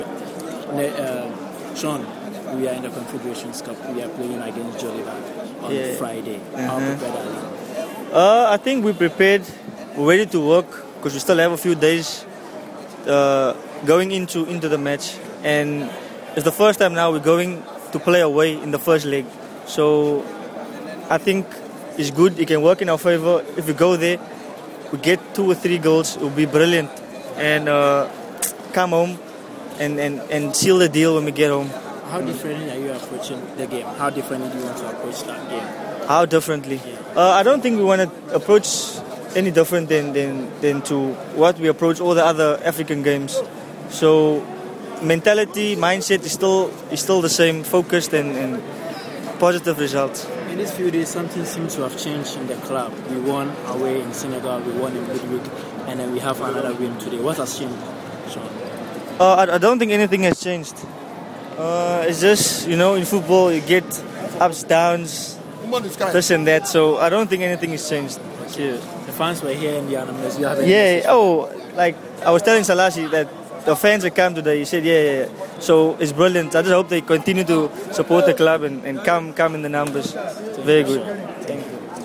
[He was speaking last Sunday after Hearts beat Wa AllStars in Accra]